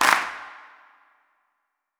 TC2 Clap9.wav